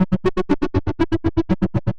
Index of /musicradar/rhythmic-inspiration-samples/120bpm
RI_ArpegiFex_120-01.wav